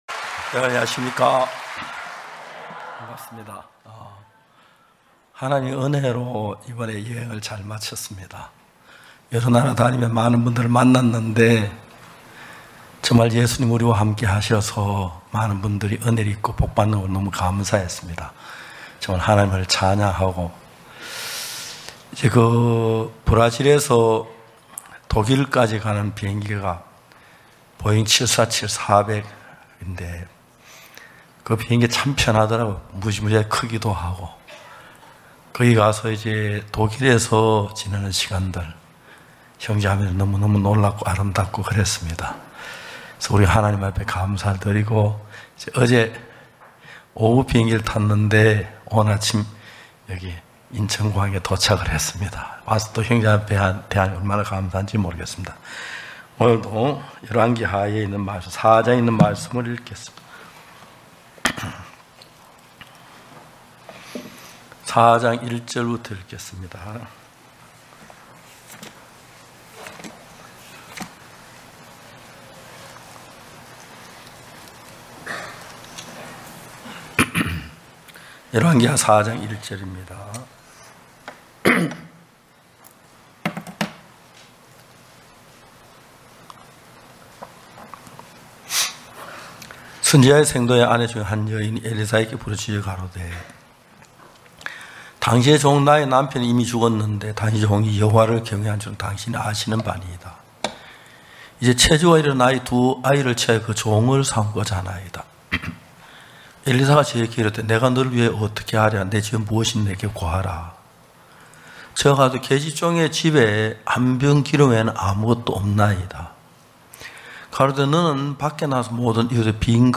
전국 각 지역의 성도들이 모여 함께 말씀을 듣고 교제를 나누는 연합예배.